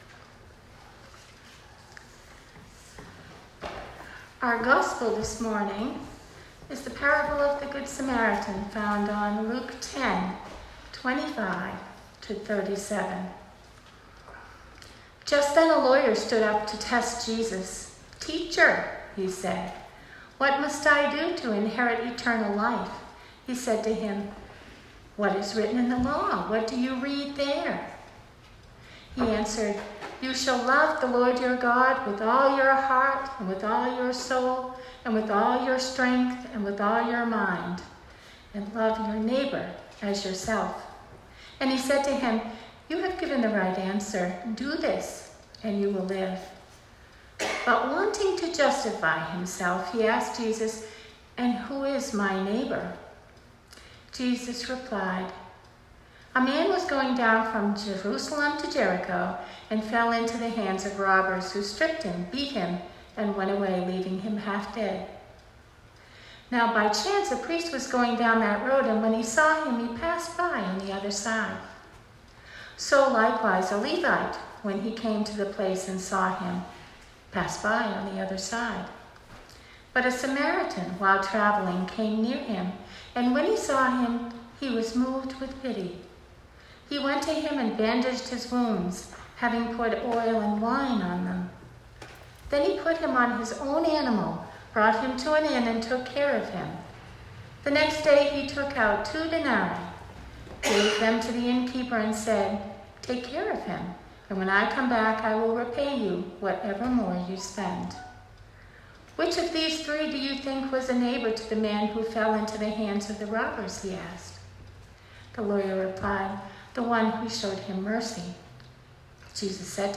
Sermon 2019-07-14